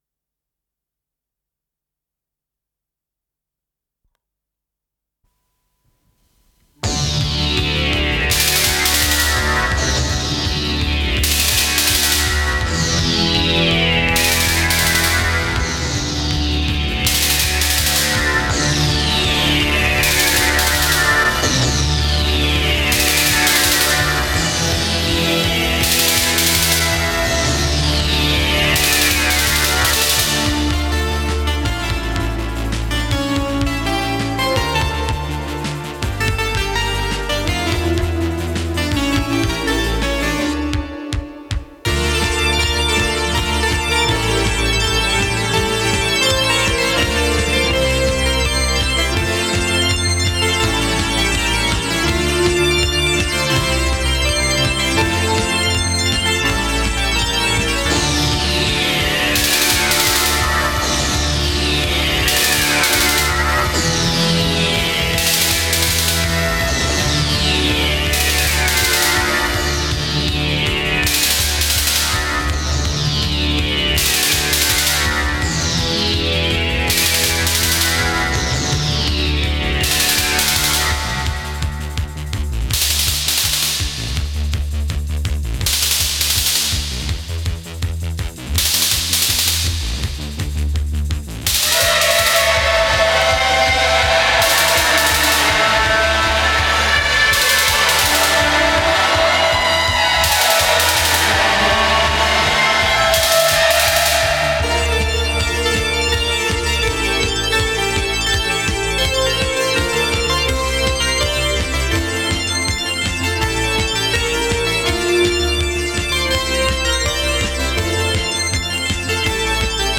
синтезатор
ударные
ВариантДубль моно